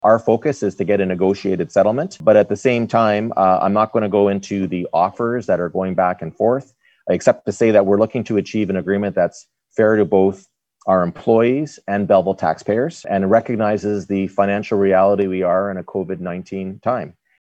Following Monday night’s council meeting, Mayor Mitch Panciuk told Quinte News, negotiations continue with those workers in the hopes of reaching a deal.